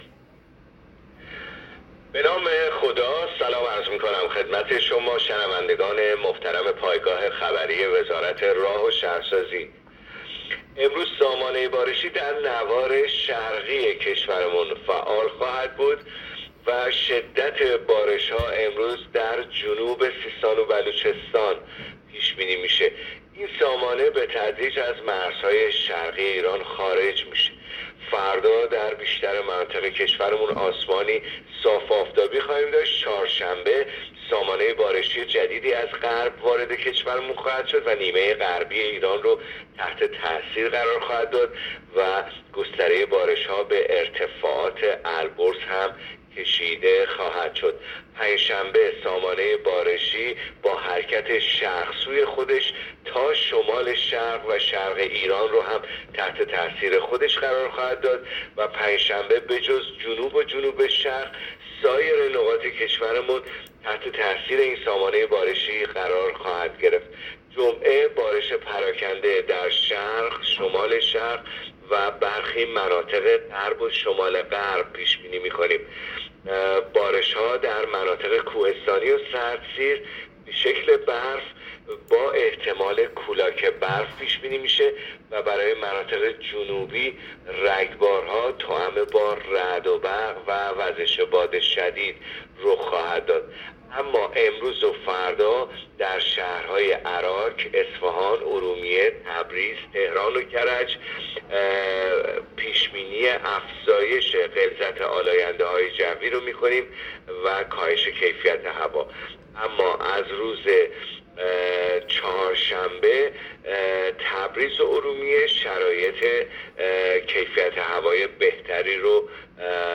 گزارش رادیو اینترنتی از آخرین وضعیت آب و هوای ششم بهمن؛